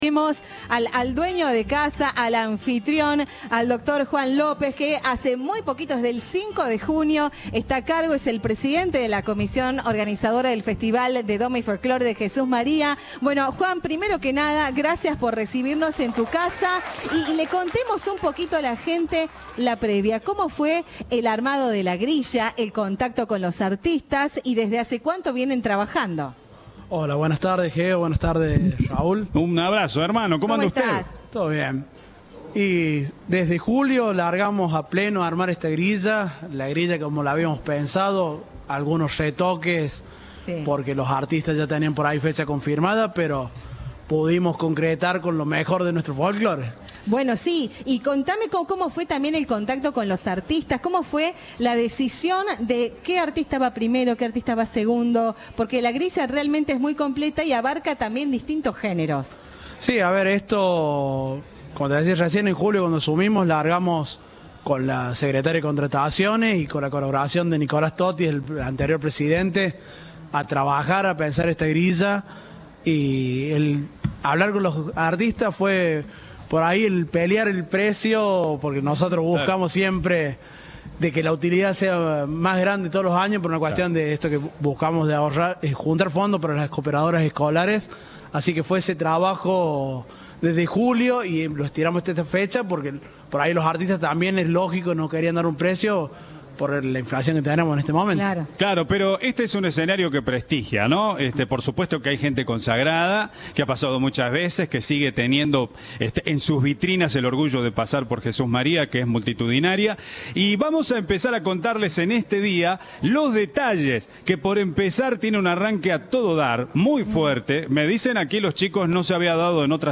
Viva la Radio transmitió desde Jesús María en el Día de la Tradición